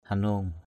/ha-no:ŋ/ (đg.) dùng tay vuốt mông (trâu, bò) cho nó đứng lại.